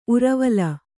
♪ uravala